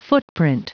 Prononciation du mot footprint en anglais (fichier audio)
Prononciation du mot : footprint